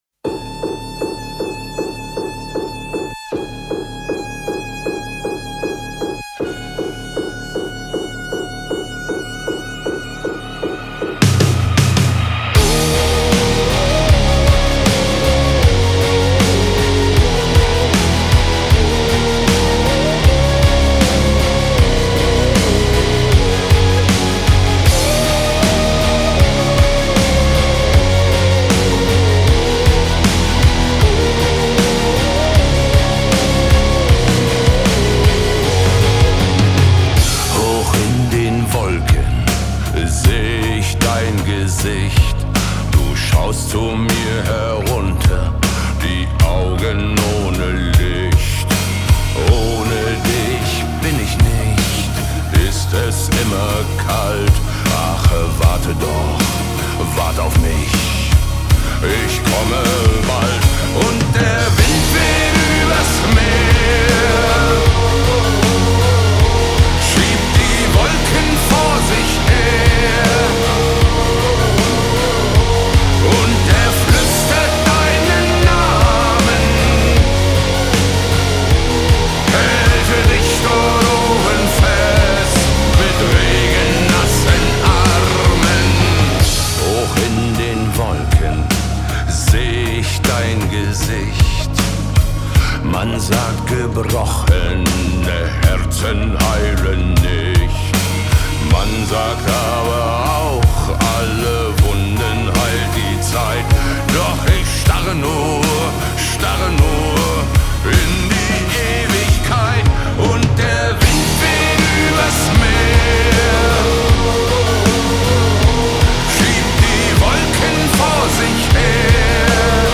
• Жанр: Metal